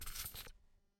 Belt Buckle
A metal belt buckle clicking open and closed with leather strap sliding through
belt-buckle.mp3